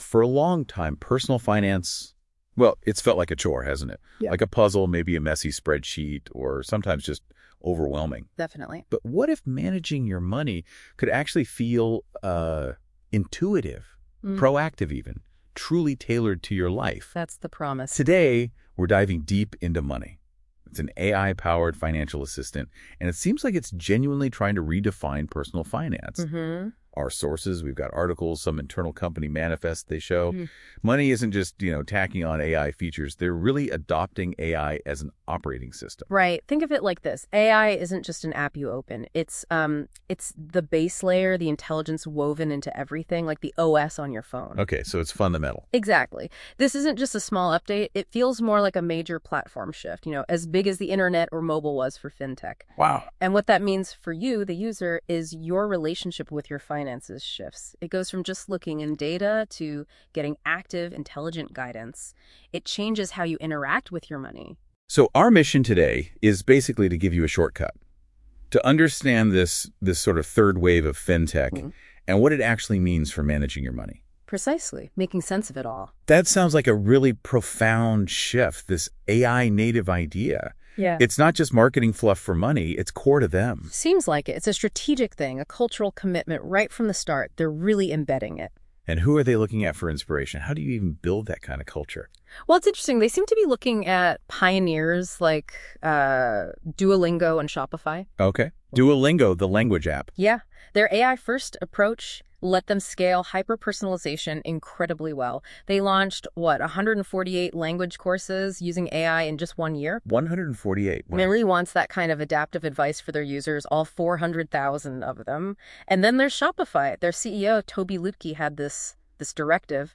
Yapay zeka, Monay'ın finansal co-pilot yaklaşımını ve para ile ilişkinizin nasıl değiştiğini analiz ediyor.